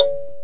Index of /90_sSampleCDs/AKAI S6000 CD-ROM - Volume 5/Africa/KALIMBA